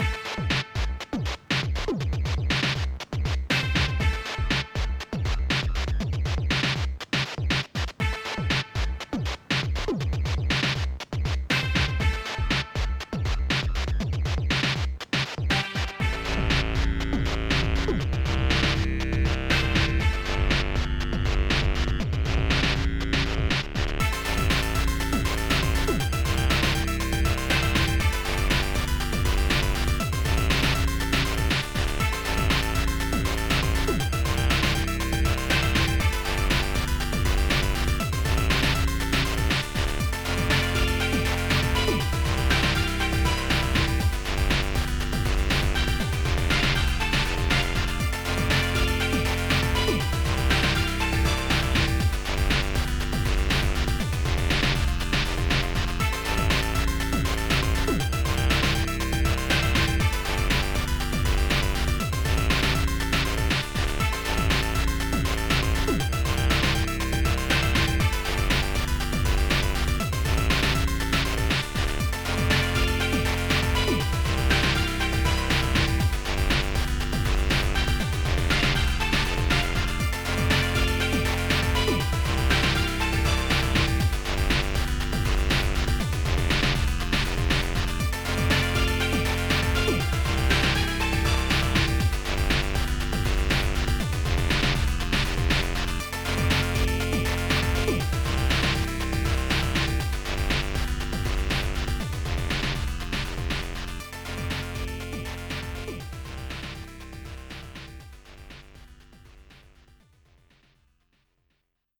Saturday fun.. bleep bloop..